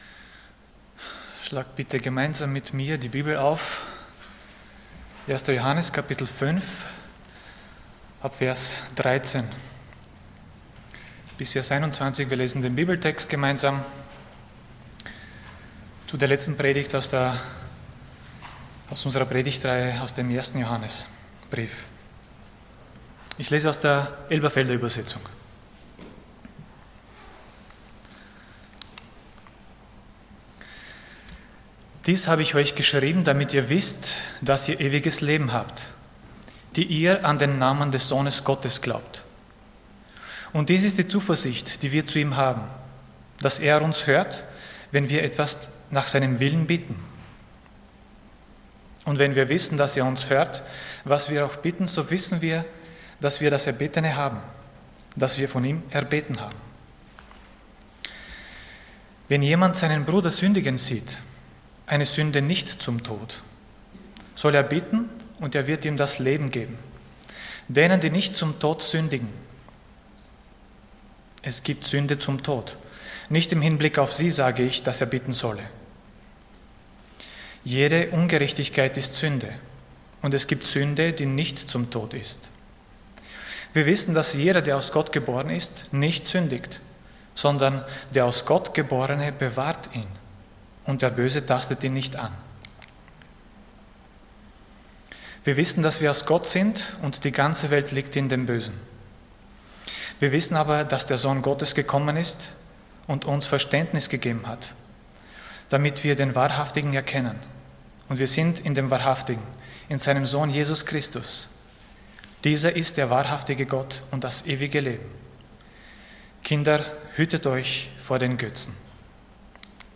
Dienstart: Sonntag Morgen